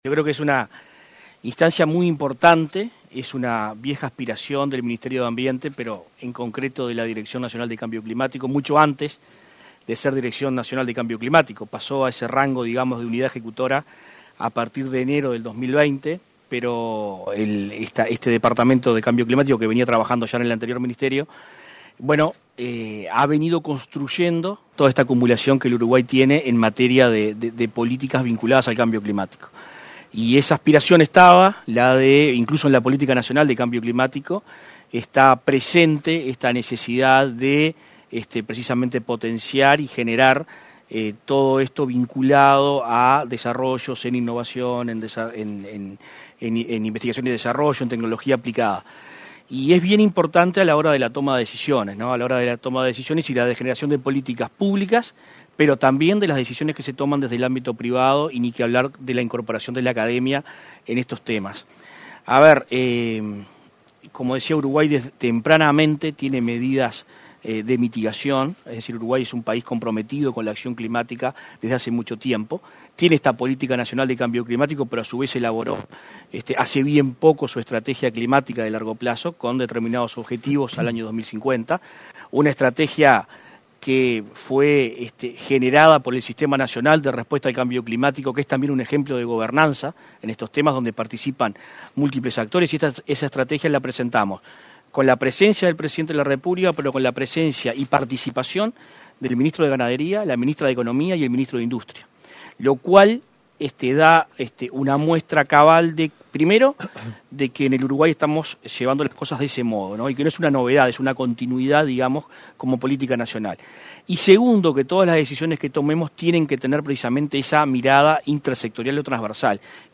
Conferencia de prensa para la convocatoria a soluciones innovadoras que incorporen tecnología para enfrentar el cambio climático
Participaron en el evento el titular de Ambiente, Adrián Peña; el ministro interino de Industria, Energía y Minería, Walter Verri, y el presidente de la ANII, Flavio Caiafa.